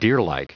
Prononciation du mot deerlike en anglais (fichier audio)
Prononciation du mot : deerlike